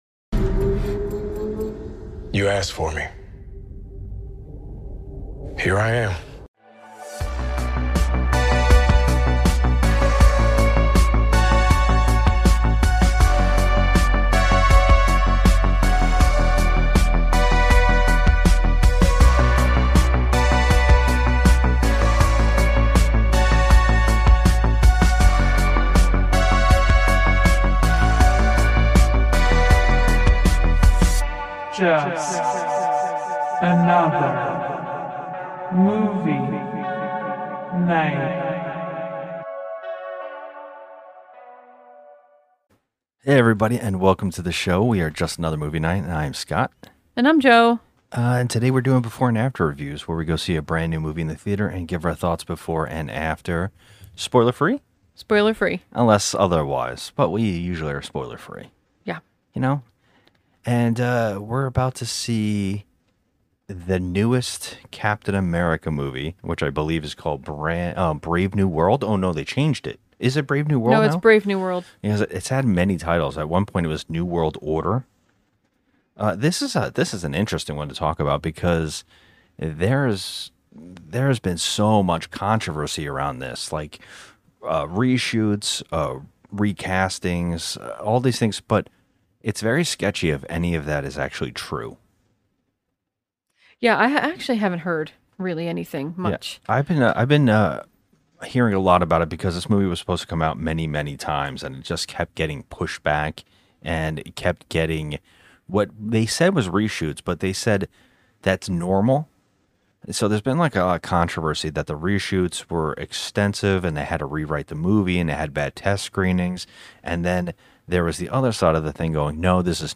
a married couple who have been watching way to much TV and Movies, for way to long. Finally we decided to get off our lazy Asses and start recording our nightly rants, reviews and conversations on all things entertainment (but mainly horror movies).